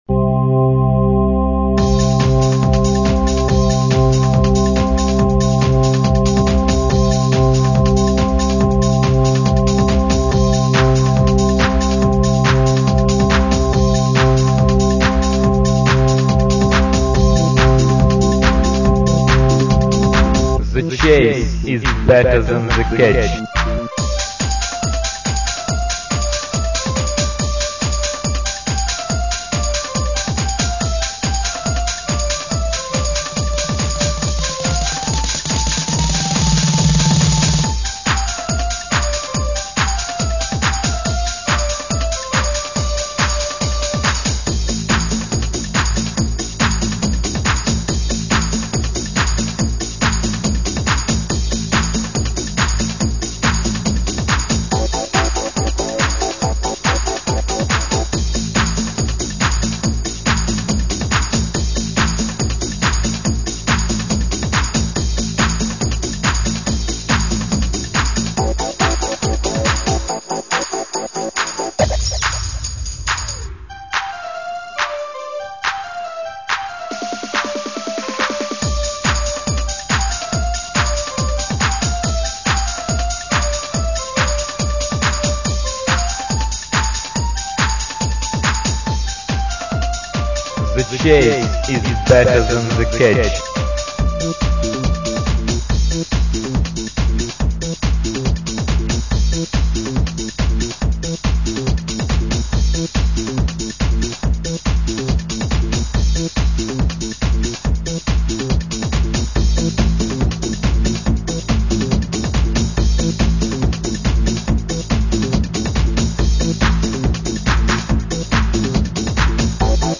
• Jakość: 22kHz, Mono